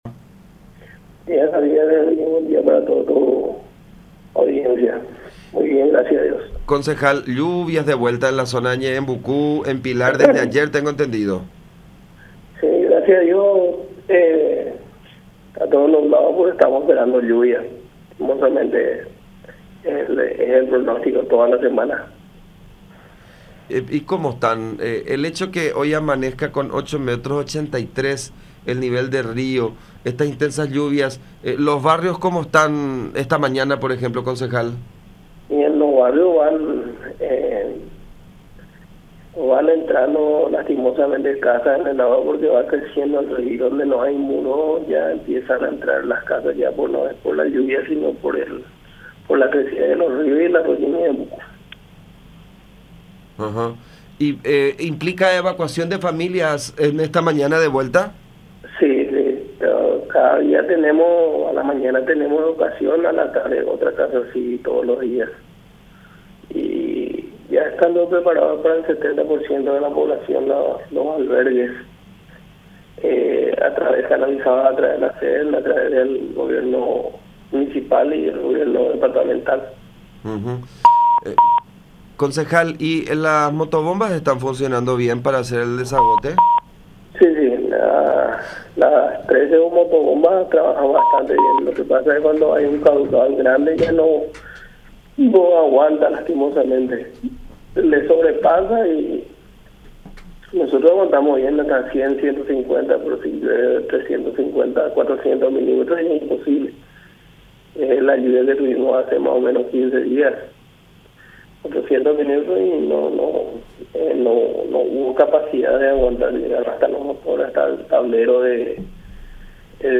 “No hay un parte del departamento de Ñeembucú donde no haya agua”, expresó en comunicación con La Unión, afirmando que esta realidad ha generado la pérdida de cuantiosos cultivos de pequeños campesinos.